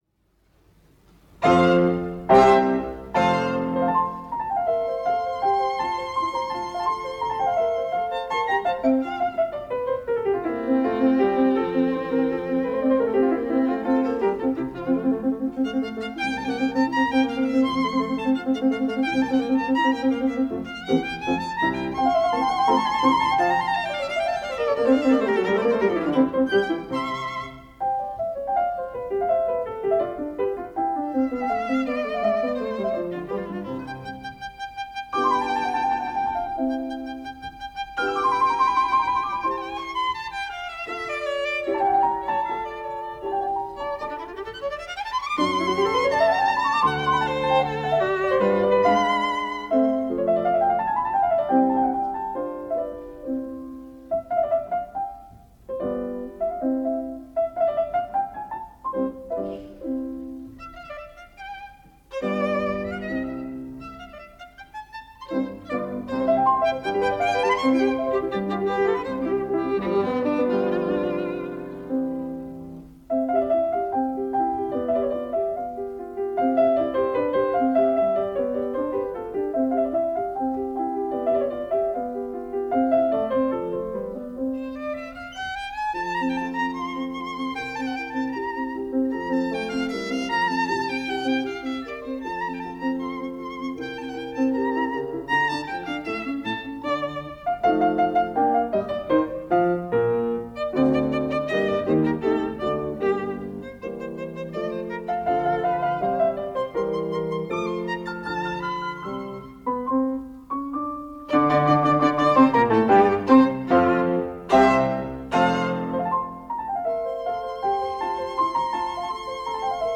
Arthur Grumiaux - Clara Haskil - Mozart - 1956 - Past Daily Sunday Gramophone - recorded June 19, 1956 - ORTF, Paris
Another historic concert tonight – this one, recorded at the Grande Salle du Palais des Fêtes de Strasbourg on June 19, 1956 features two legends: Arthur Grumiaux, violin and Clara Haskil, piano.
They play the Sonata for Violin and Piano K376 by Mozart.